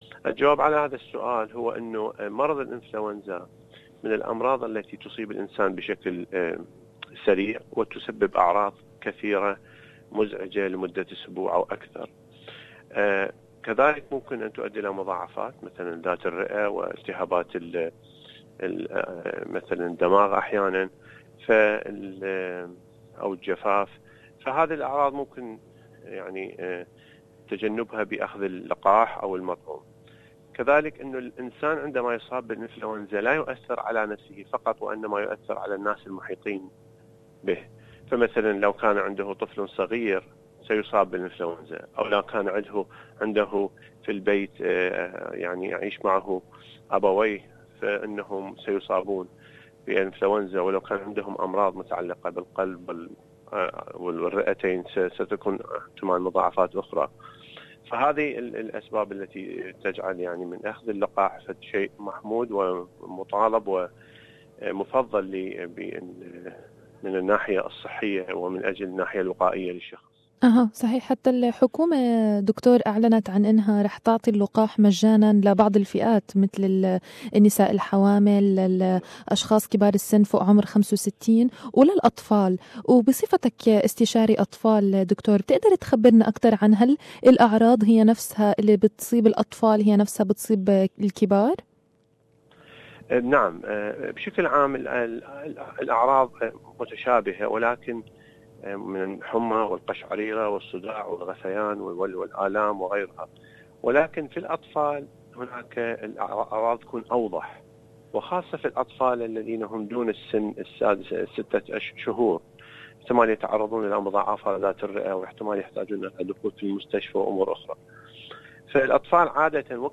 Flu Vaccine - Interview